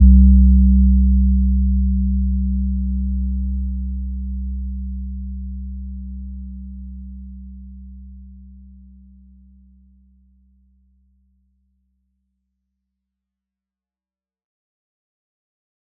Gentle-Metallic-1-C2-p.wav